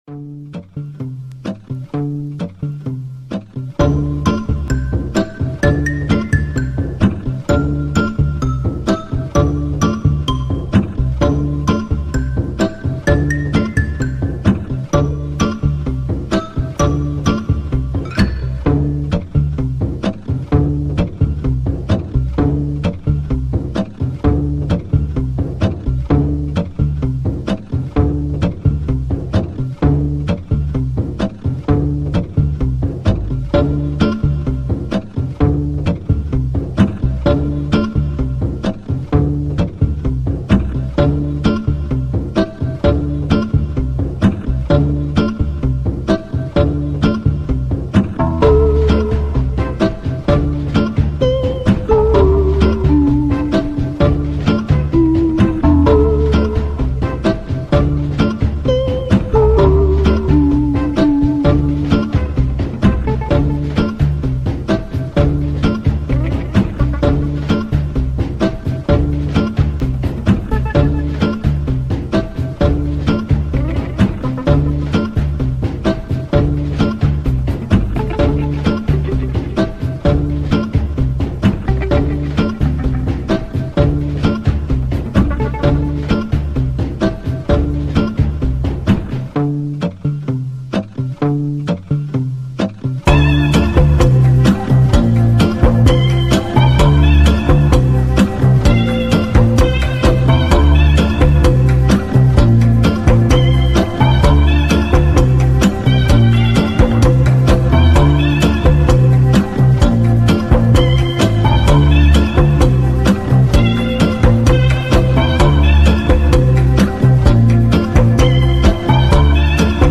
2025 in Hip-Hop Instrumentals